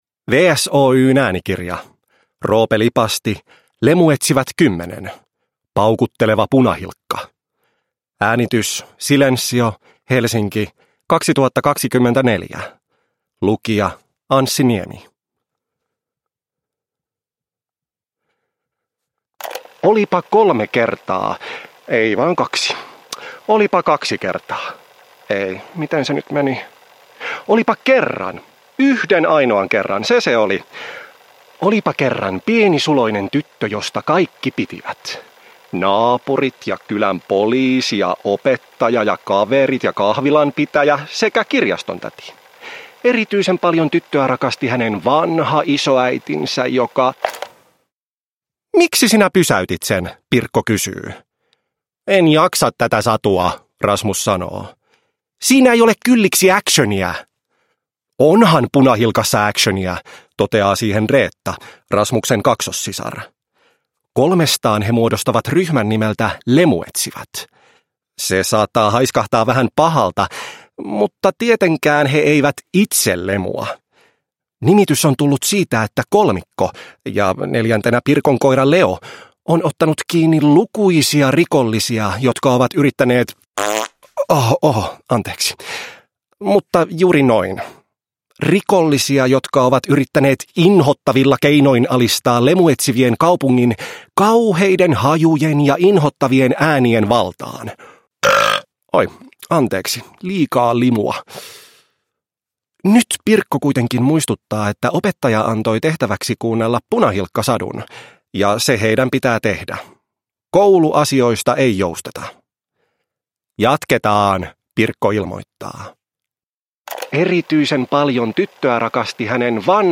Lemuetsivät 10: Paukutteleva Punahilkka (ljudbok) av Roope Lipasti